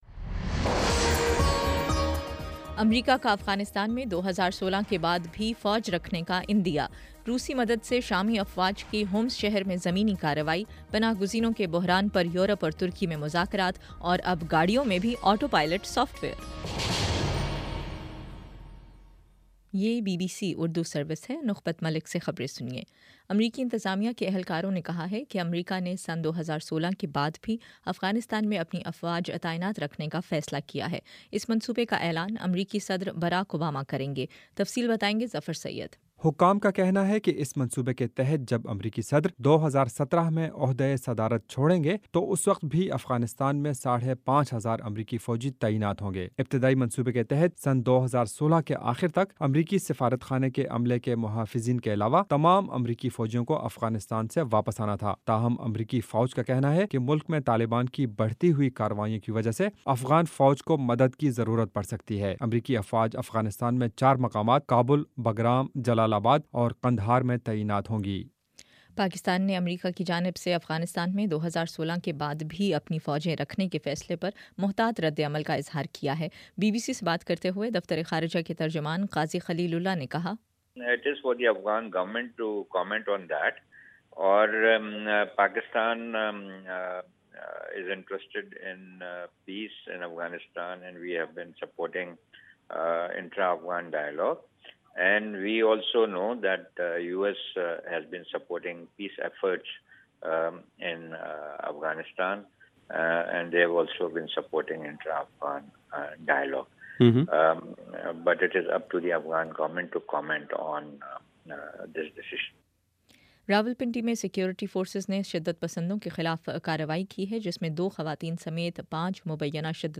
اکتوبر15: شام سات بجے کا نیوز بُلیٹن